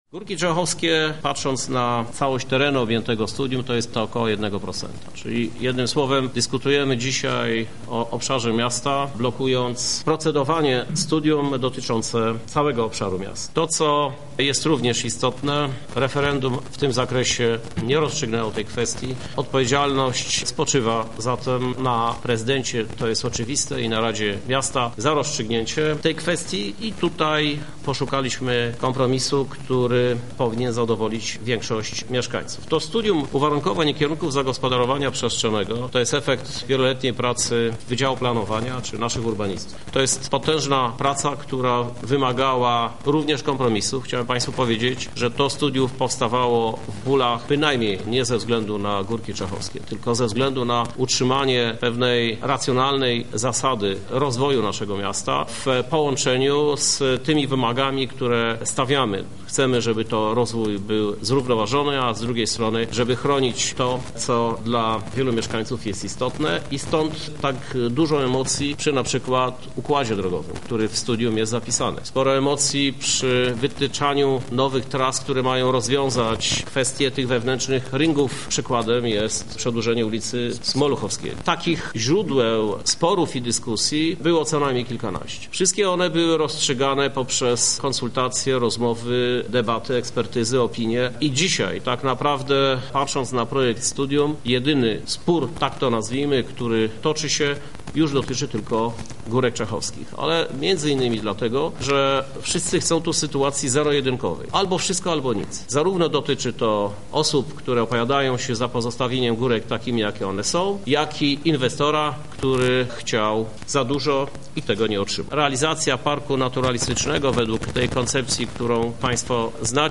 Nie jest tajemnicą, że uchwalenie studium było wstrzymywane ze względu na emocje i spory toczące się niemalże wyłącznie wokół Górek Czechowskich – mówi Prezydent miasta Lublin, Krzysztof Żuk.